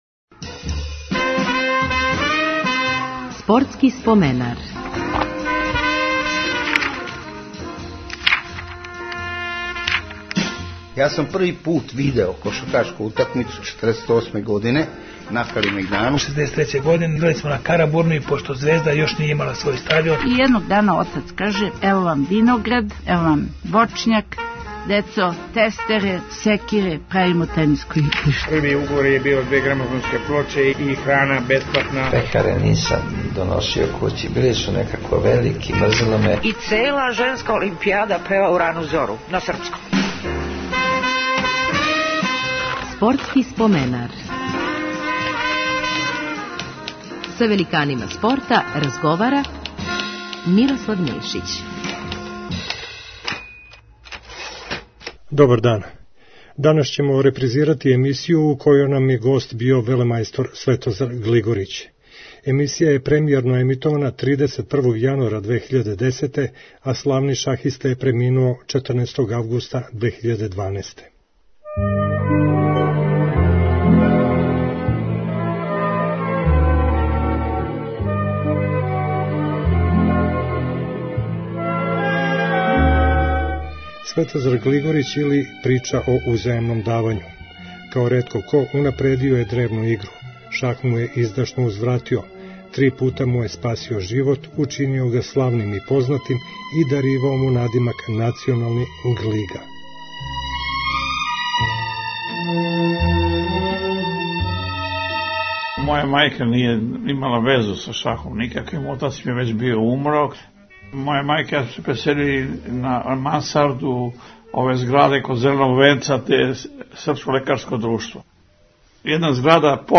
Репризирамо емисију у којој нам је гост био велемајстор Светозар Глигорић, који је преминуо пре пет година, 14. августа 2012.